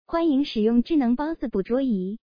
pwron.mp3